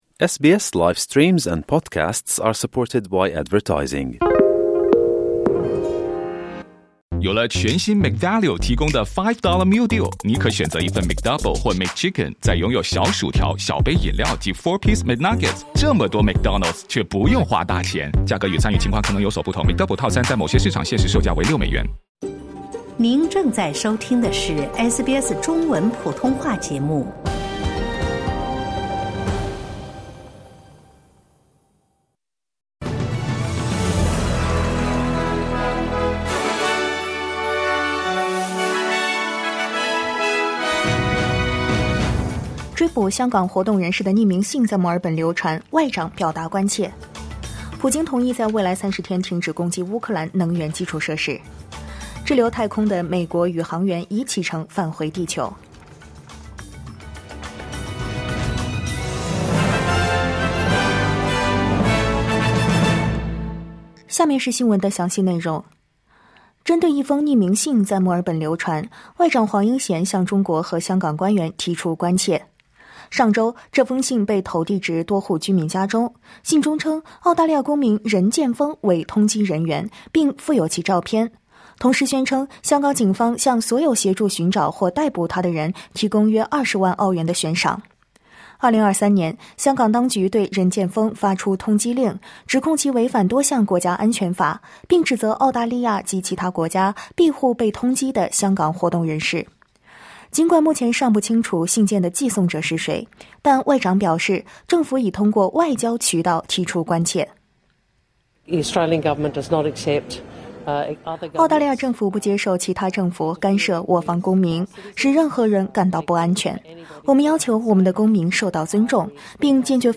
SBS早新闻（2025年3月19日）